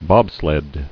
[bob·sled]